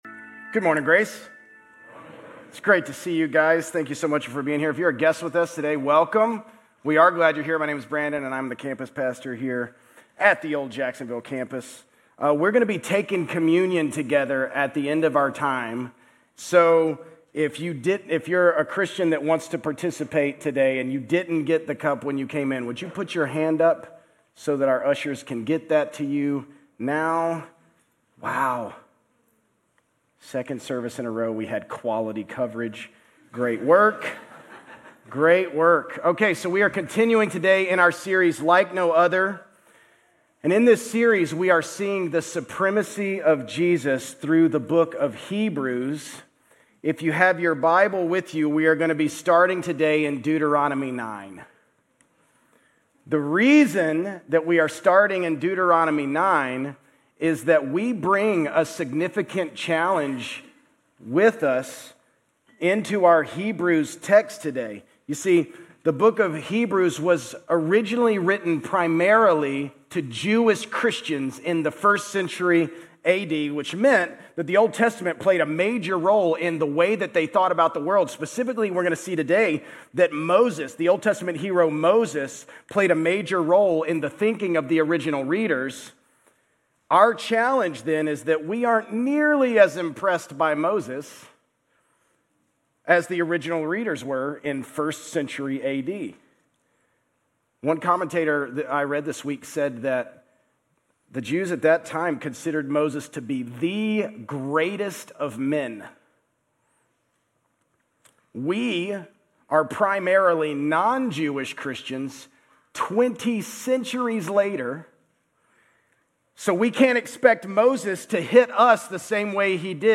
Grace Community Church Old Jacksonville Campus Sermons 10_5 Old Jacksonville Campus Oct 06 2025 | 00:37:46 1x 00:00 / 00:37:46 Subscribe Share RSS Feed Share Link Embed